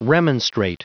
Prononciation du mot remonstrate en anglais (fichier audio)
Prononciation du mot : remonstrate